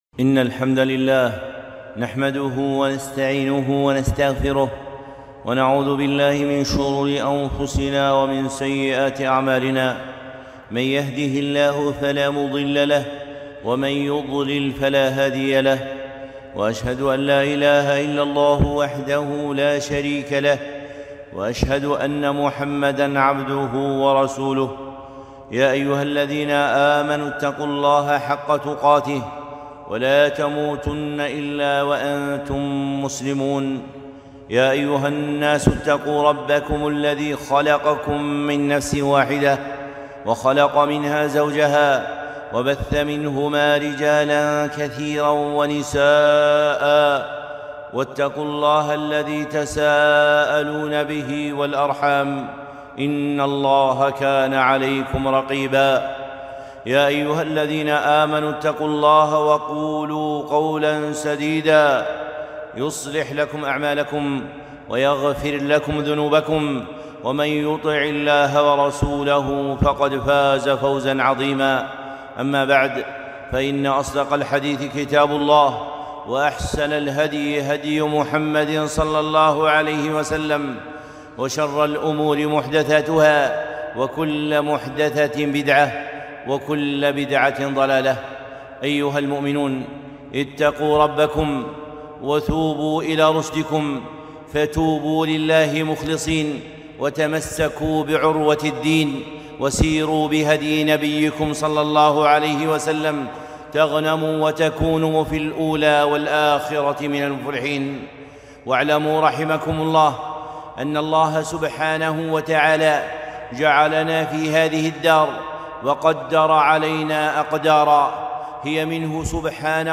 خطبة - العِبر في مشاهد القدر